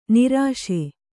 ♪ nirāśe